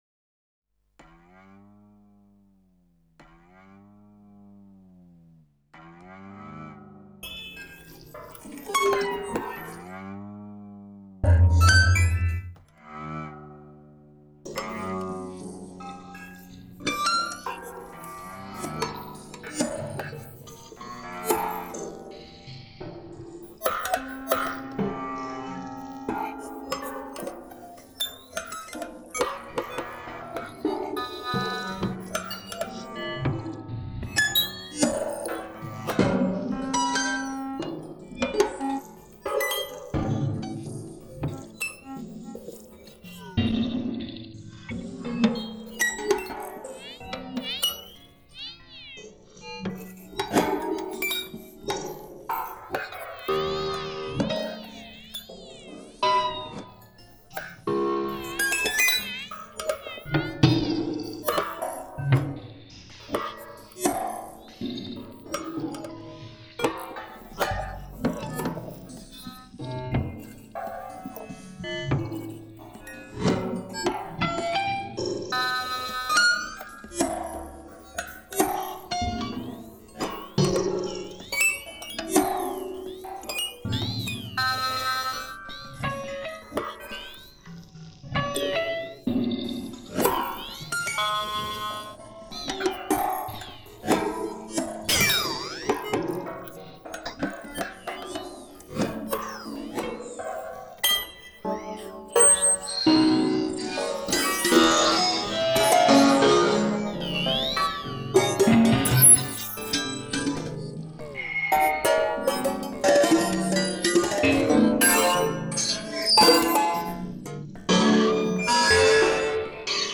Augmenting Percussion with Electronics in Improvised Music Performance
Keywords: Improvised music, improvisation, live electronics, digital electronics, analogue electronics, hybrid instrument, electroacoustic music, solo percussion, Human Computer Interaction